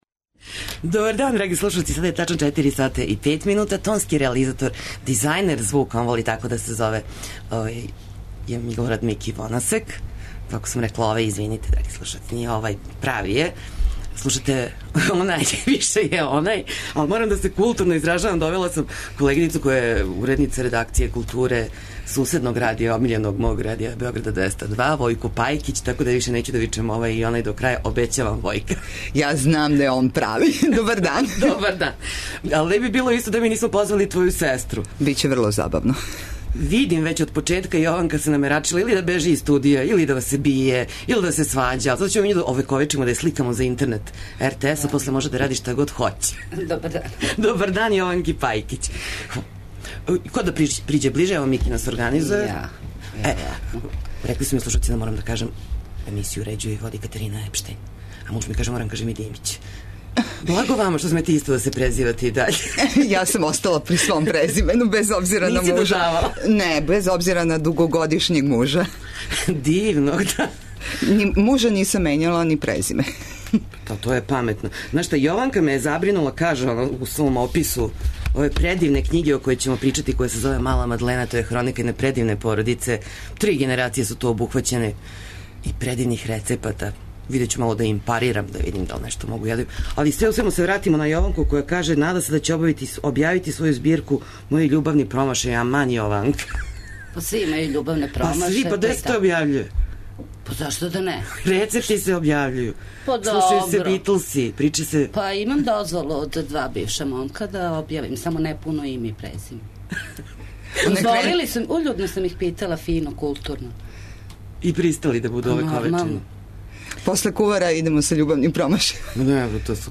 Емисија из домена популарне културе.